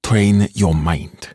召唤少林寺武僧攻击敌人，语音多与武术有关并带有严重的口音。